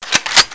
assets/psp/nzportable/nzp/sounds/weapons/mg42/charge.wav at 29b8c66784c22f3ae8770e1e7e6b83291cf27485